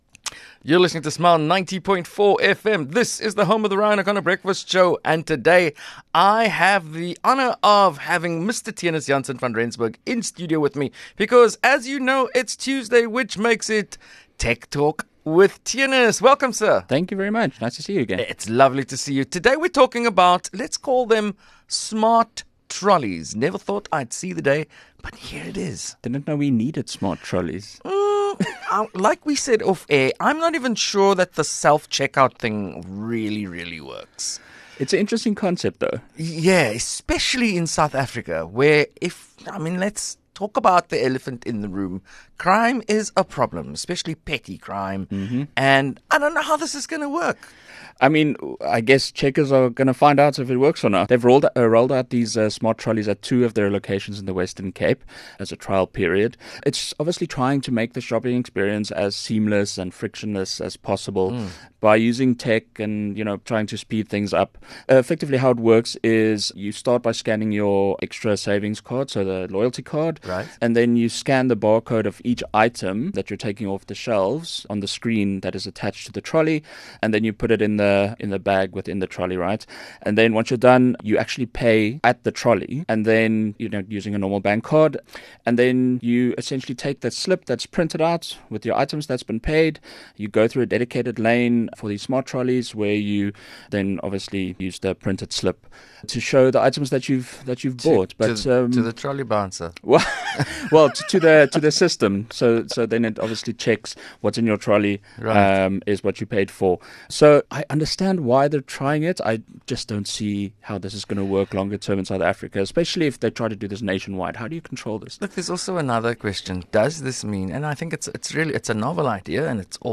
Tech Talk